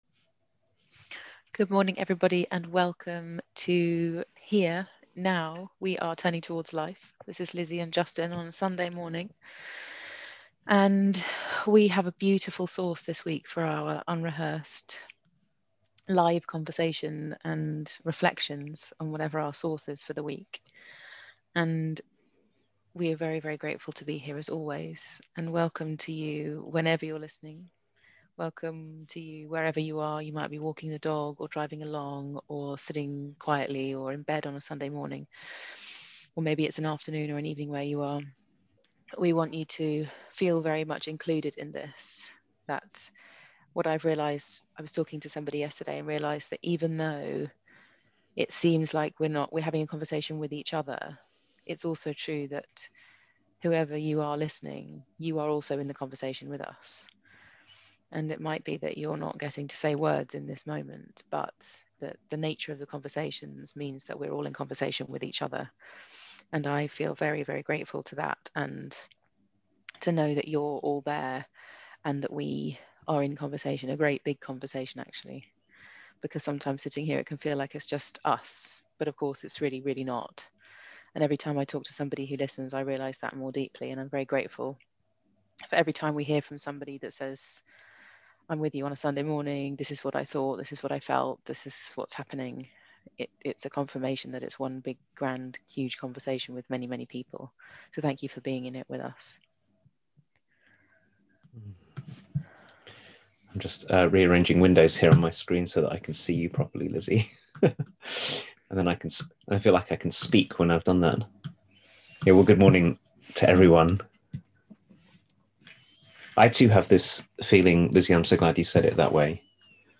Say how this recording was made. a weekly live 30 minute conversation hosted by Thirdspace